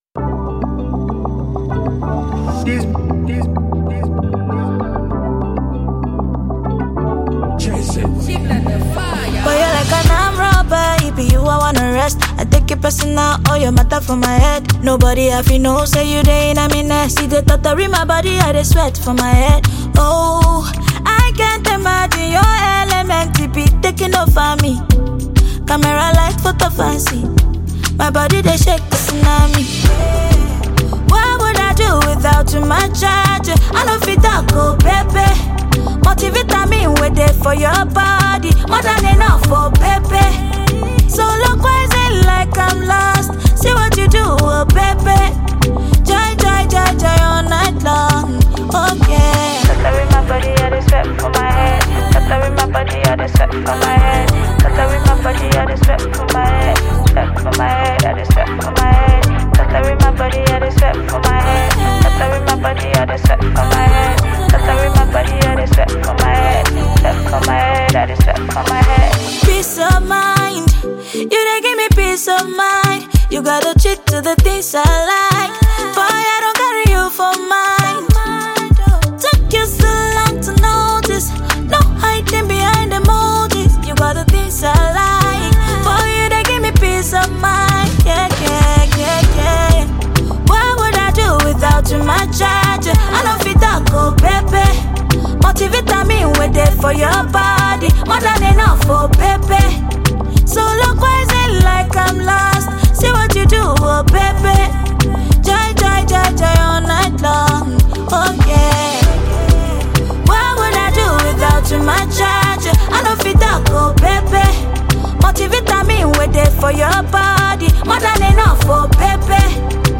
Super talented Nigerian singer, songwriter and Tiktoker